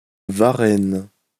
来自 Lingua Libre 项目的发音音频文件。 语言 InfoField 法语 拼写 InfoField varenne 日期 2021年2月18日 来源 自己的作品
pronunciation file